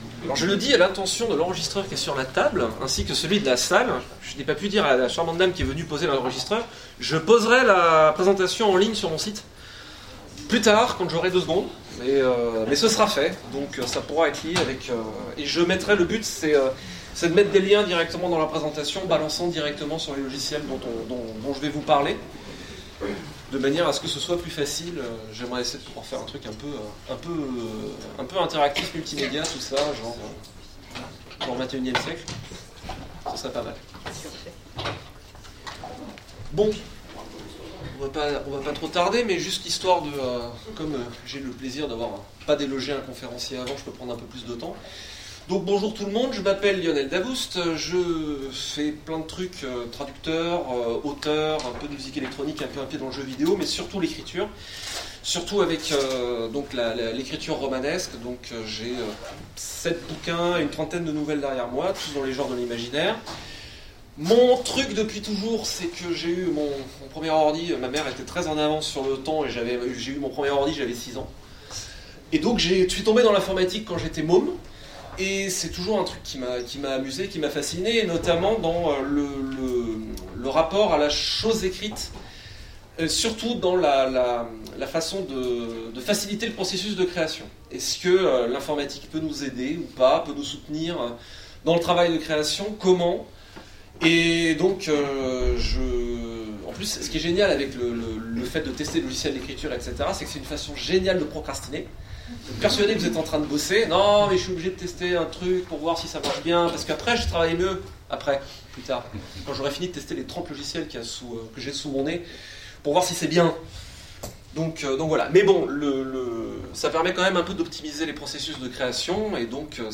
Utopiales 2016 : Cours du soir Ecrire avec des logiciels